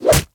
punch8.ogg